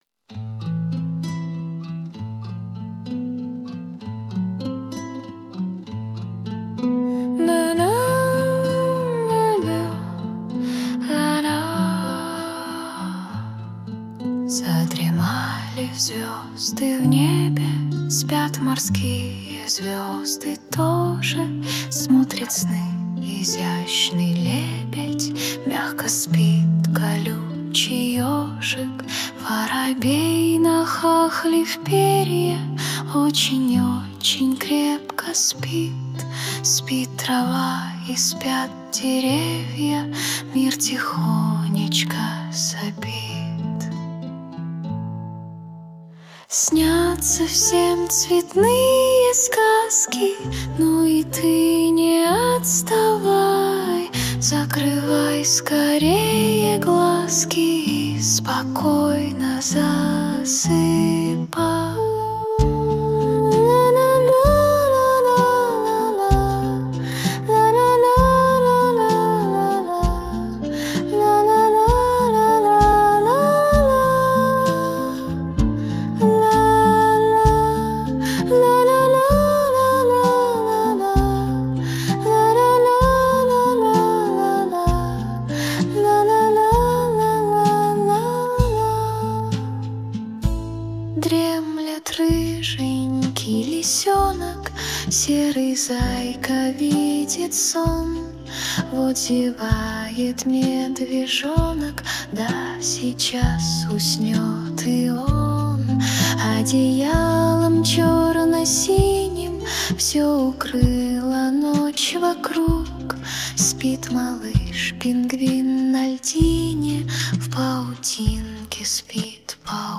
• Жанр: Детские песни
🎶 Детские песни / Колыбельные песни